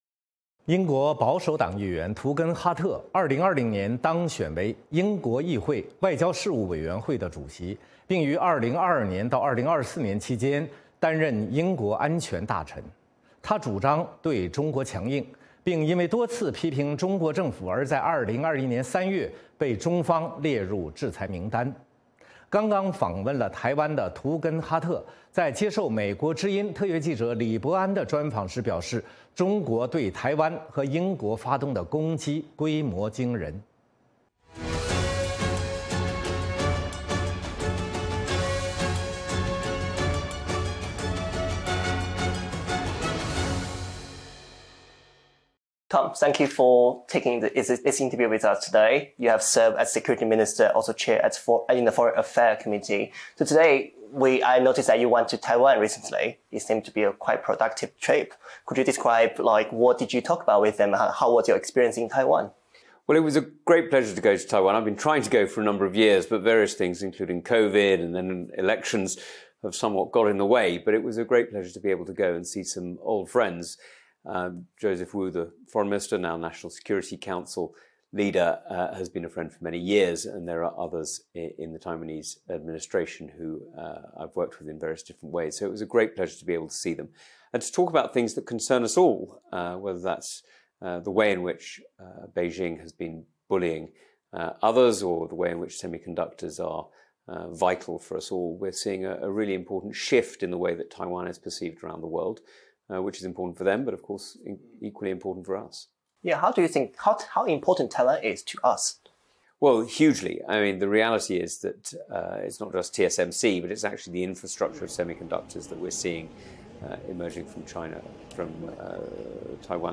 《纵深视角》节目进行一系列人物专访，受访者发表的评论不代表美国之音的立场 。